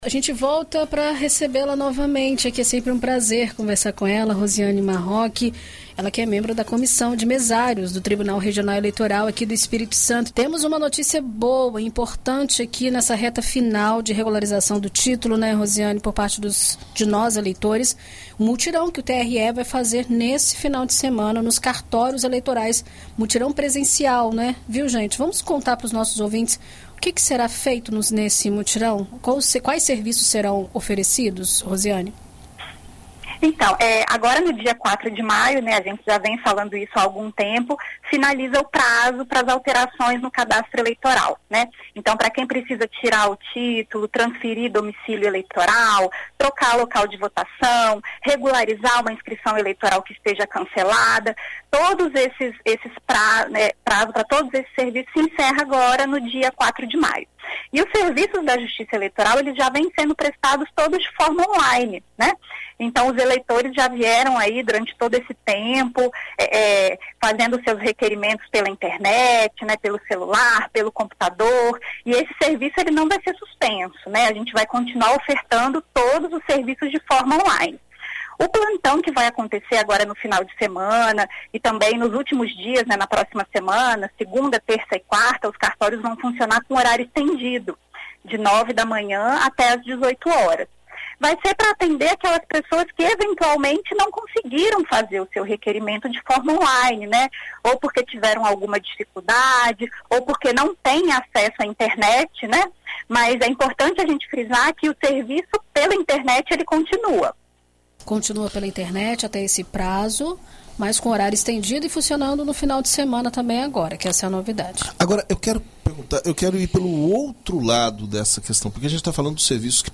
Em entrevista à BandNews FM Espírito Santo nesta sexta-feira